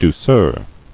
[dou·ceur]